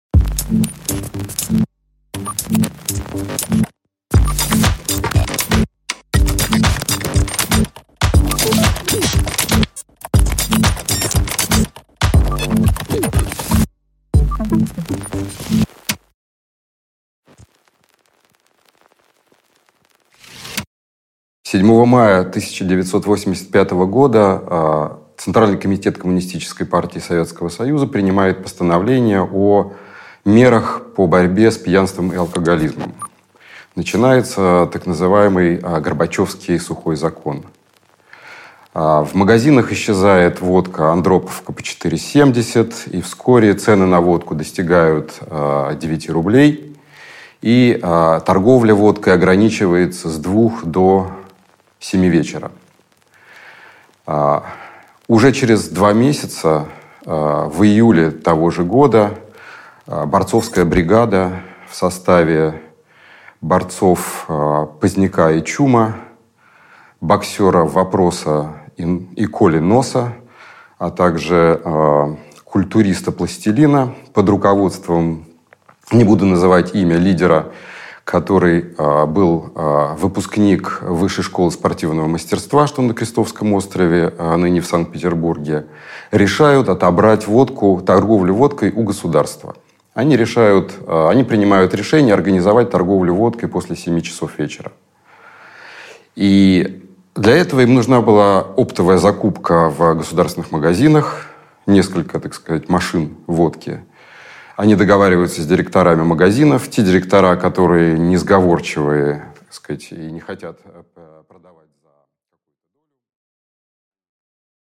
Аудиокнига Русь бандитская | Библиотека аудиокниг